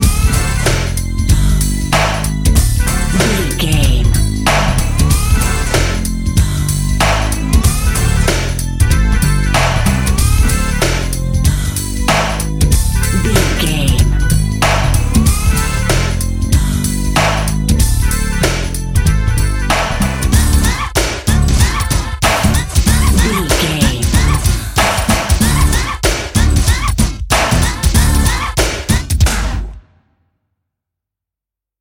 Aeolian/Minor
drum machine
synthesiser
Eurodance